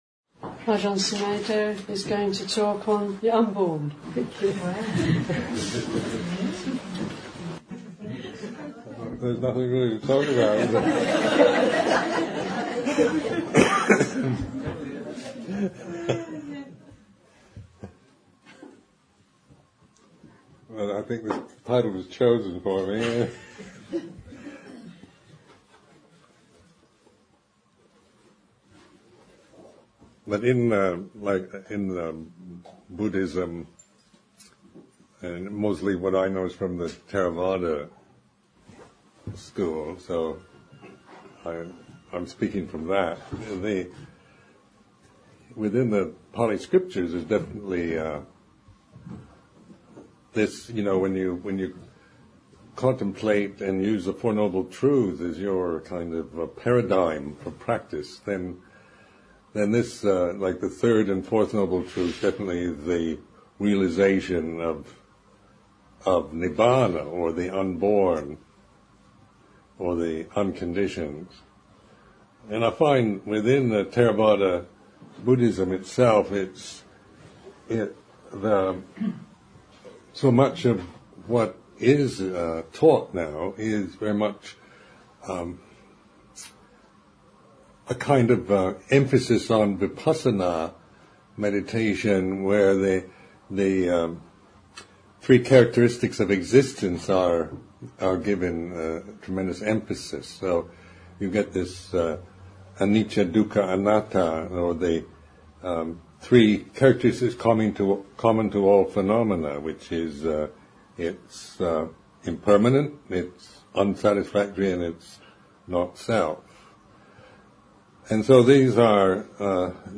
Buddhist talk given at the 2001 Buddhist Publishing Group Summer School.